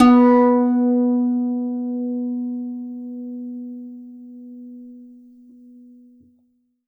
52-str09-zeng-b2.aif